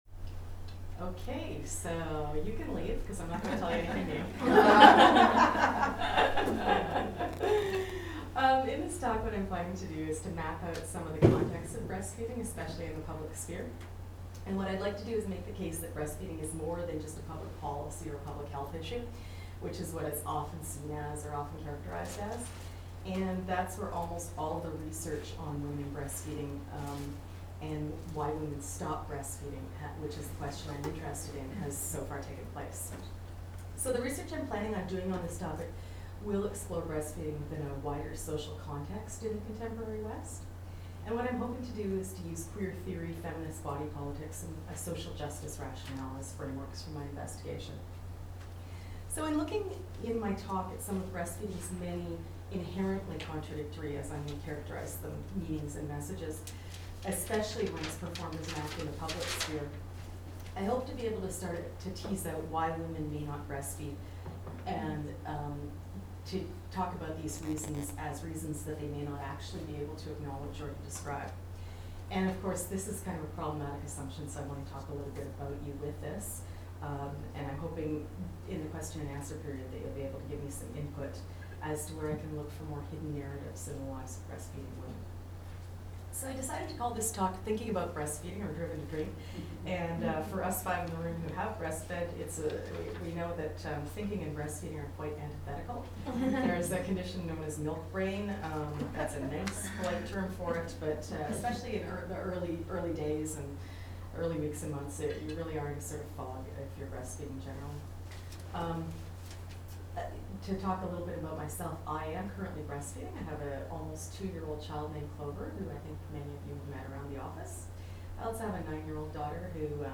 Today’s talk explores breastfeeding’s many inherently contradictory meanings and messages, especially when it is performed as an act in the public sphere.